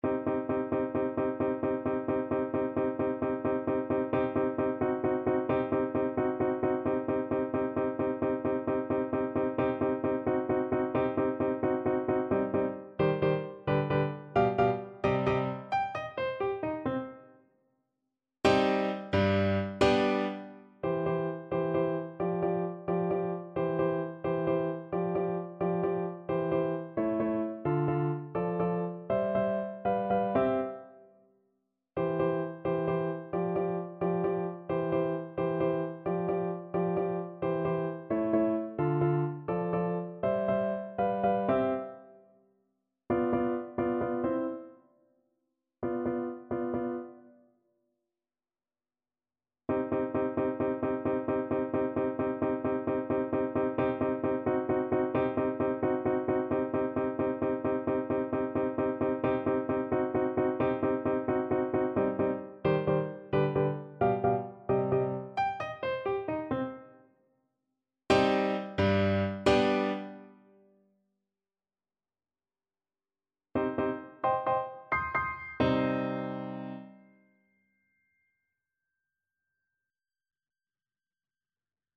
3/8 (View more 3/8 Music)
Classical (View more Classical Clarinet Music)